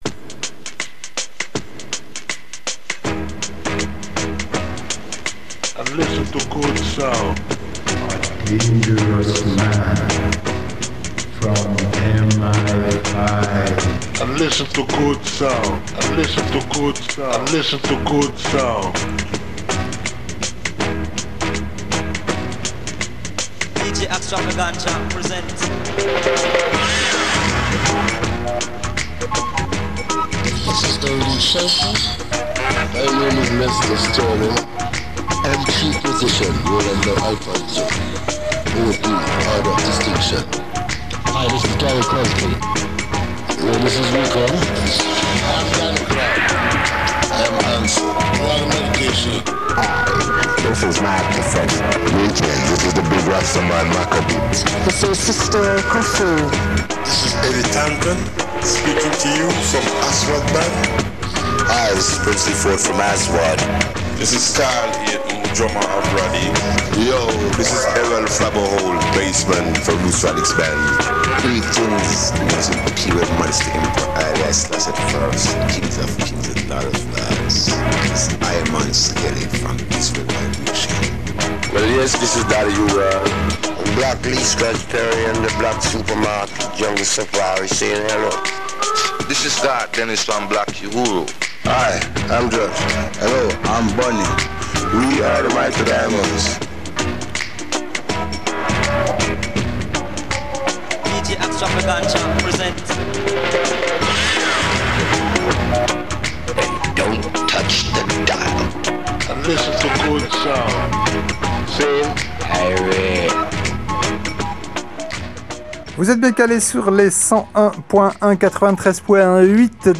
Black Super Market – radio show !
ska, afrobeat, dub, salsa, funk, mestizo, kompa, rumba, reggae, soul, cumbia, ragga, soca, merengue, Brésil, champeta, Balkans, latino rock…